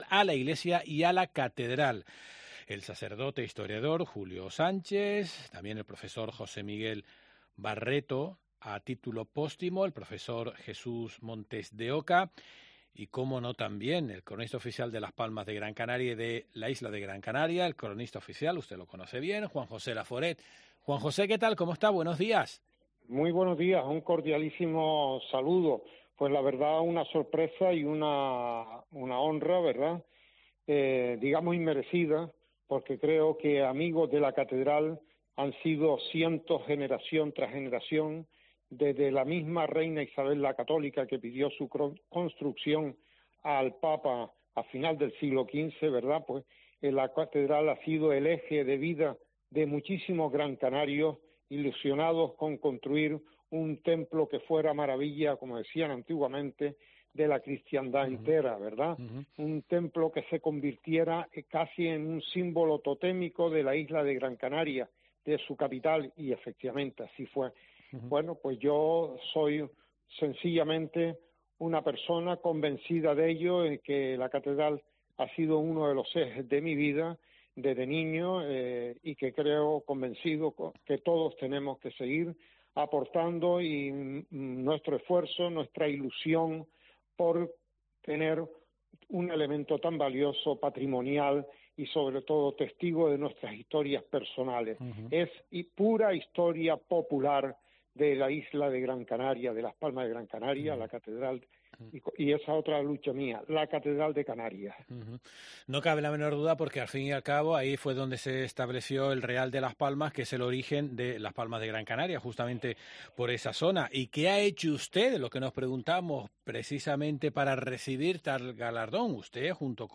Juan José Laforet, cronista oficial de Las Palmas de Gran Canaria